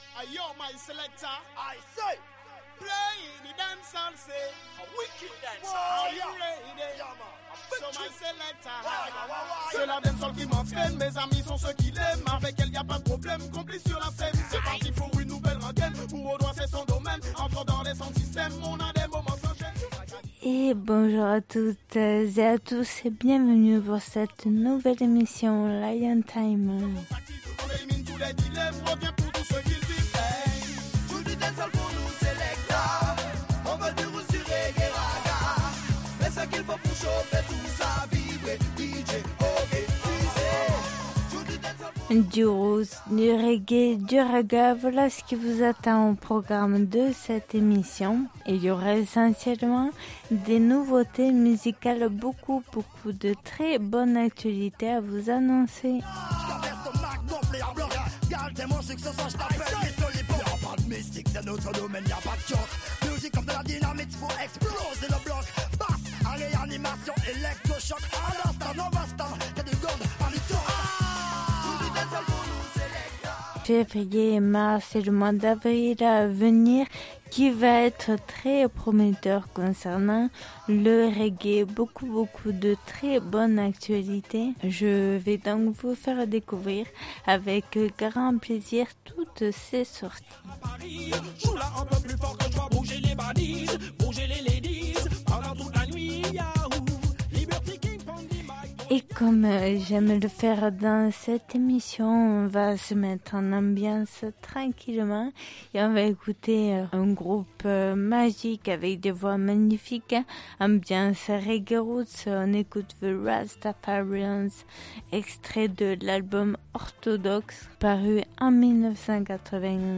Émission qui invite au voyage et à la découverte du roots, du reggae et du dancehall.